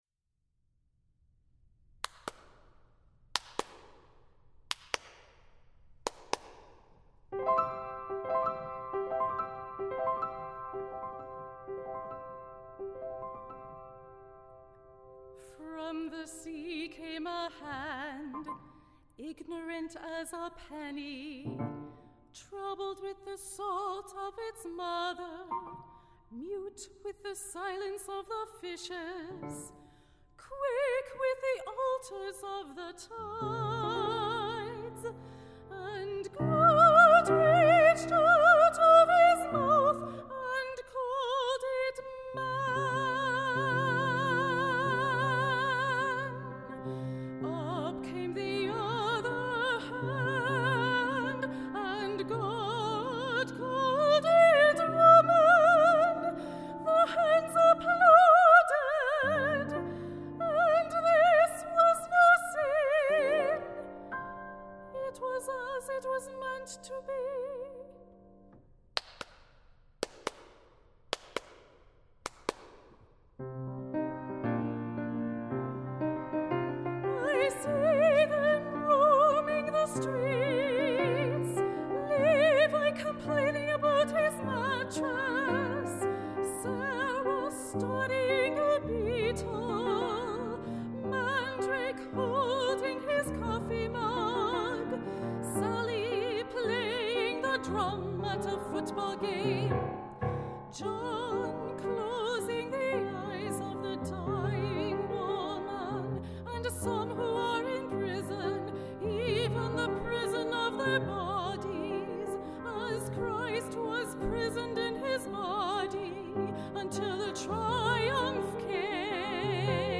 for High Voice and Piano (2013)
soprano
piano